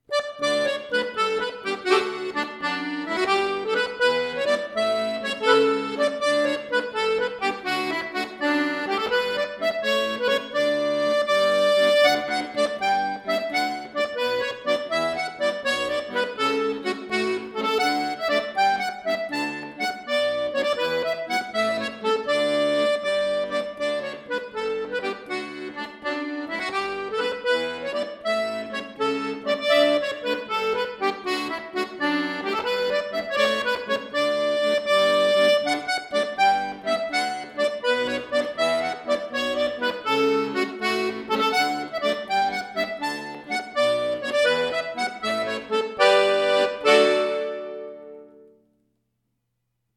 Folk
Irish